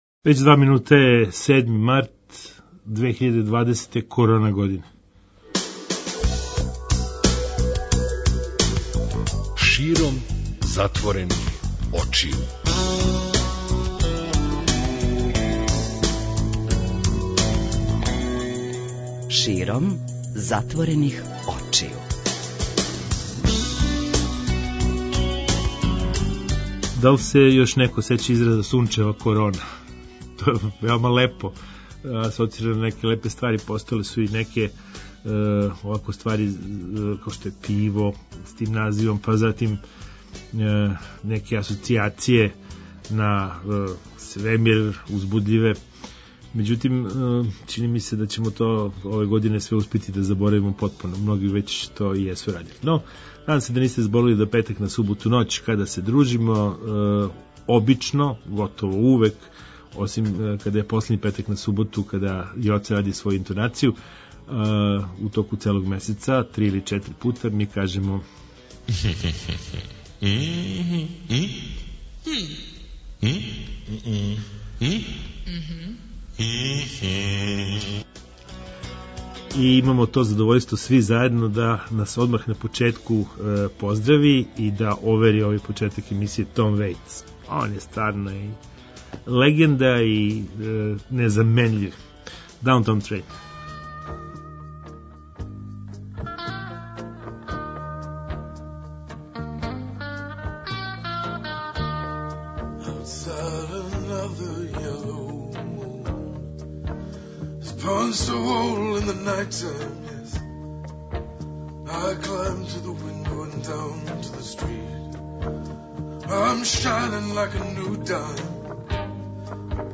Зато ћемо и ноћас да причамо о неколико тема уз непрекидан контакт са публиком.